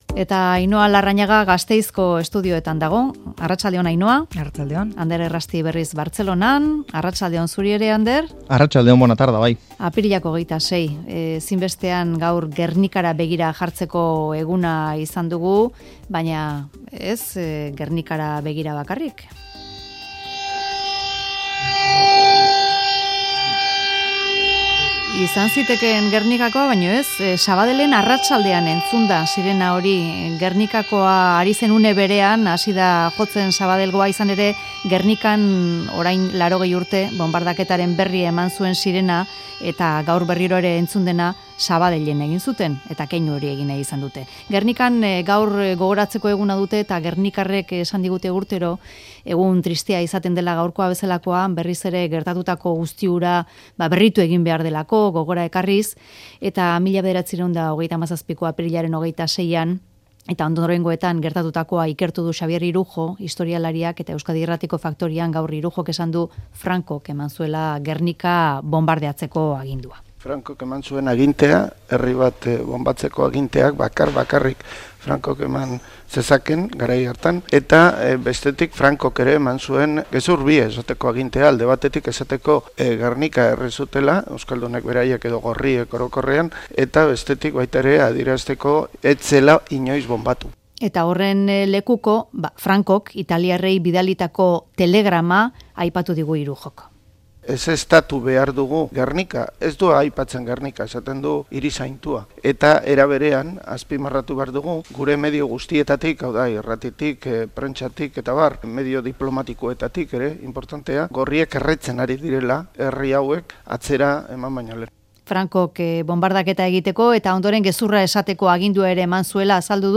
Mezularia|Solasaldia